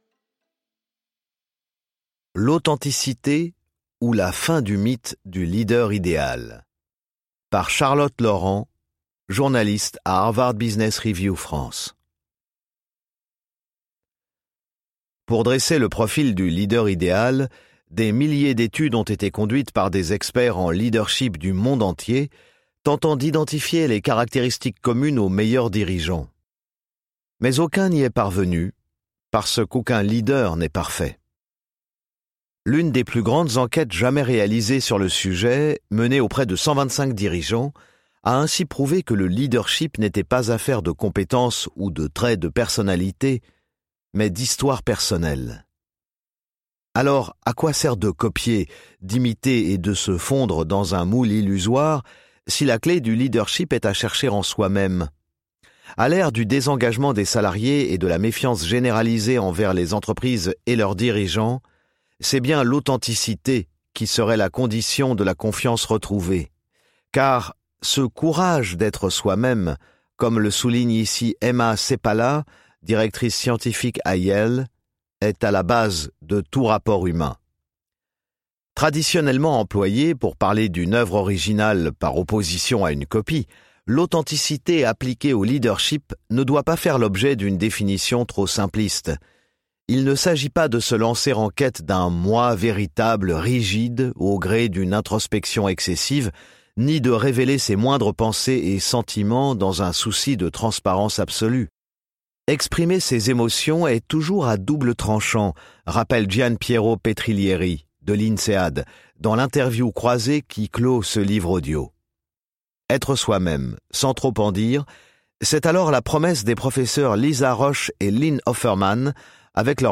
je découvre un extrait - Leadership authentique de Harvard Business Review